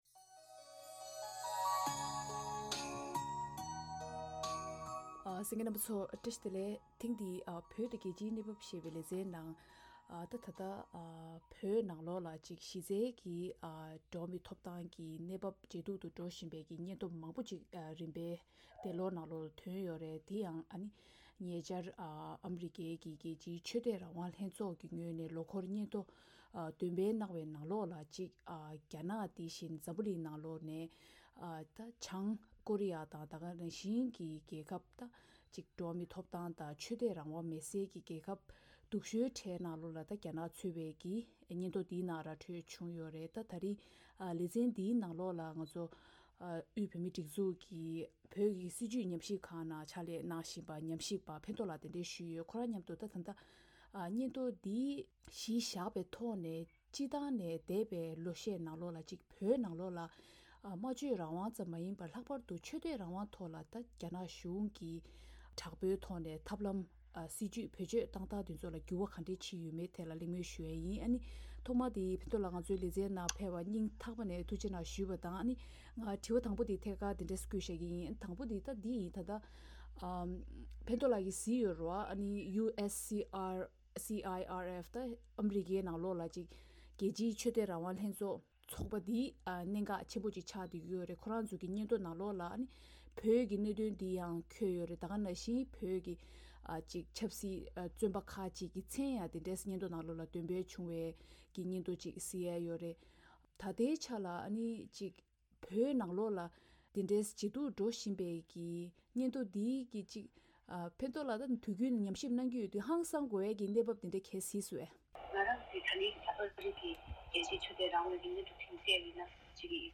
གཉིས་བར་གླེང་མོལ་གནང་བར་གསན་རོགས་ཞུ